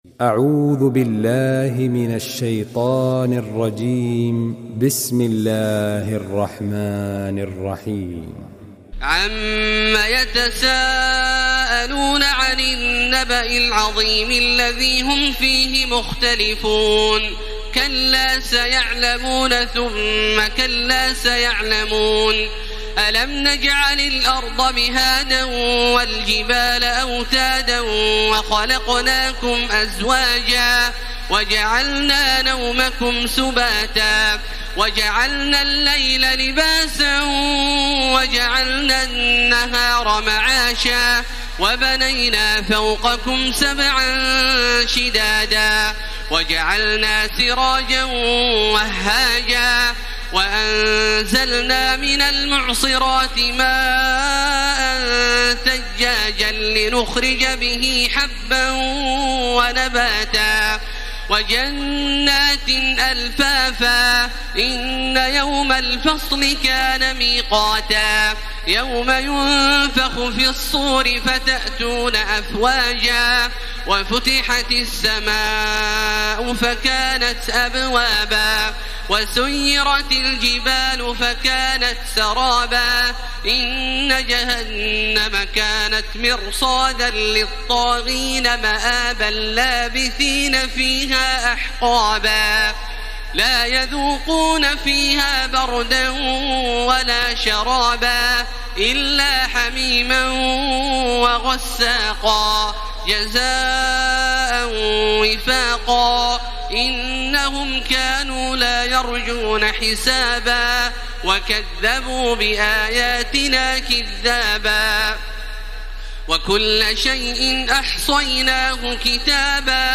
تراويح ليلة 29 رمضان 1434هـ من سورة النبأ الى الليل Taraweeh 29 st night Ramadan 1434H from Surah An-Naba to Al-Lail > تراويح الحرم المكي عام 1434 🕋 > التراويح - تلاوات الحرمين